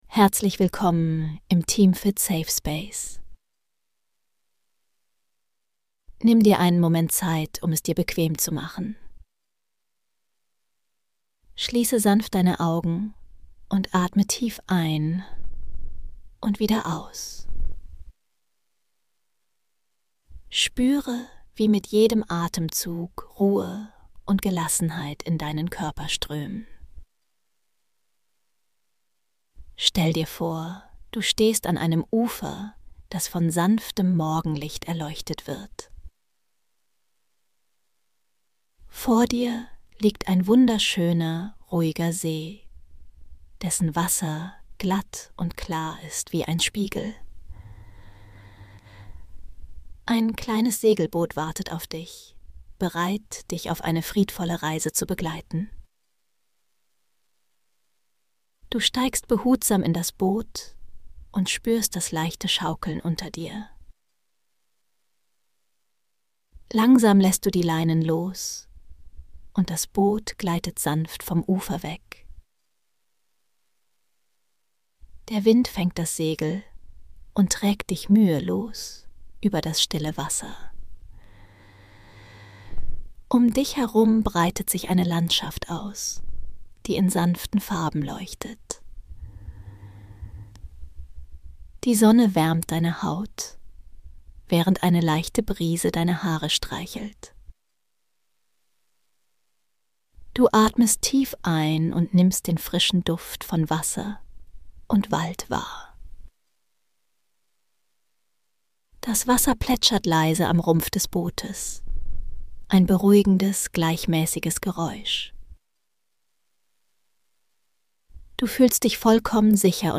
Entspannende Traumreise: Eine friedvolle Segelbootfahrt auf einem